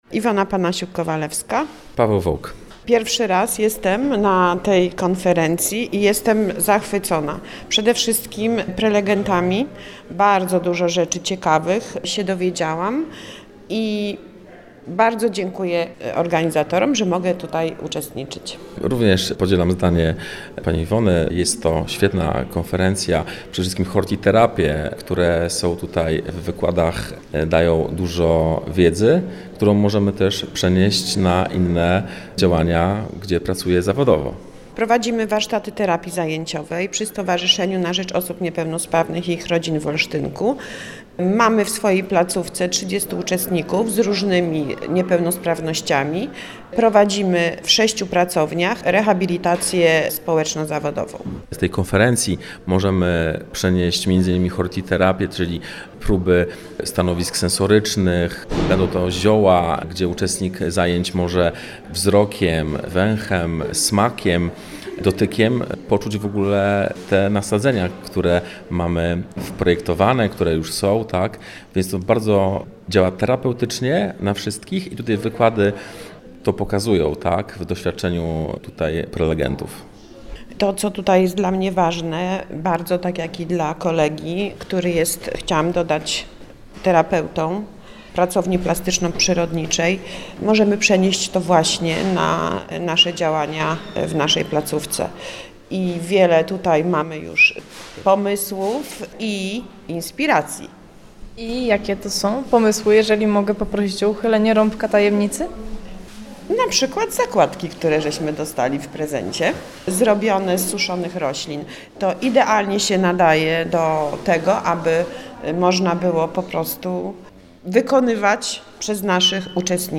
O wrażenia z konferencji zapytaliśmy także innych jej uczestników.